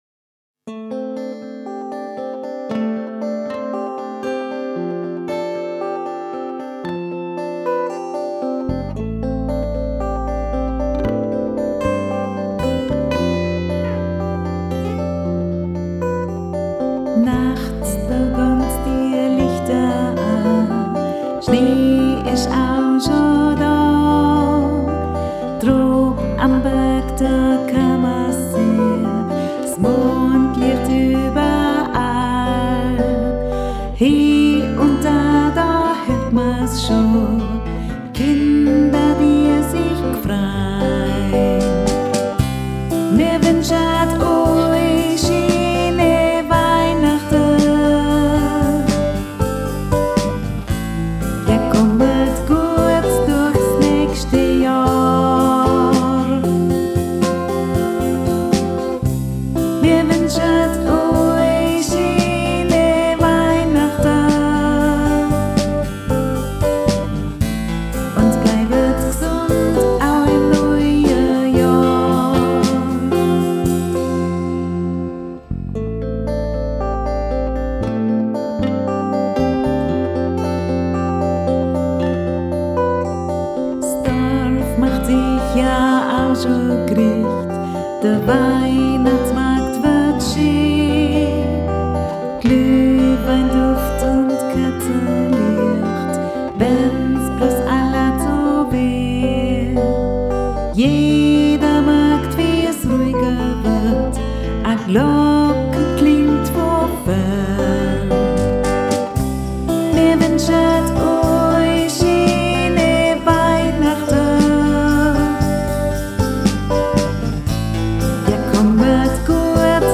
Aufnahmesessions (Demo-Aufnahmen) bei mir im Home-Studio: